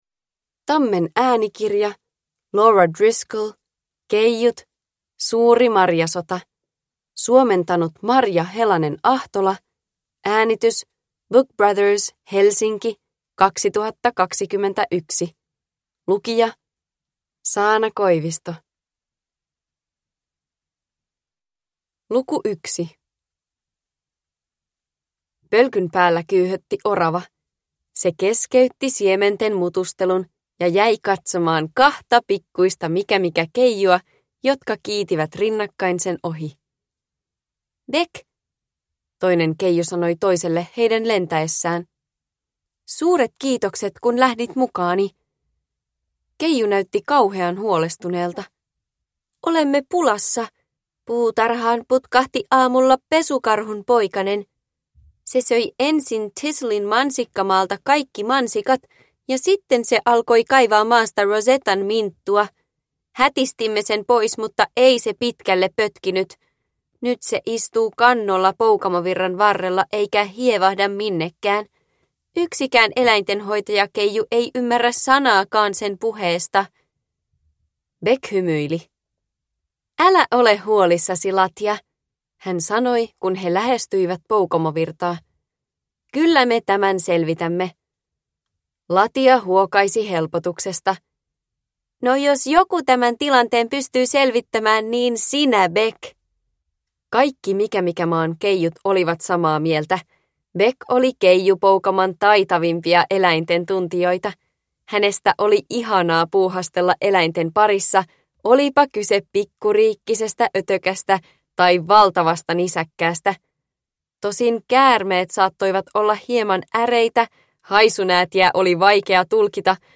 Keijut. Suuri marjasota – Ljudbok – Laddas ner